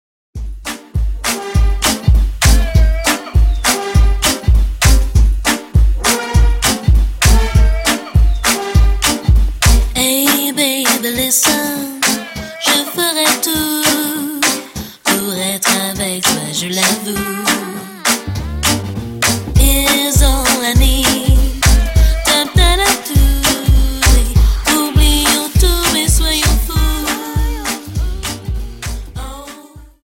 Dance: Quickstep 50